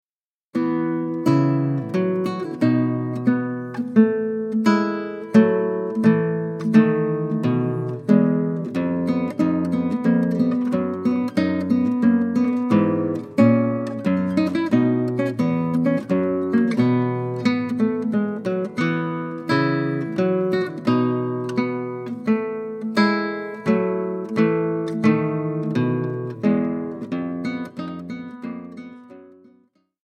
Tablatures pour Guitare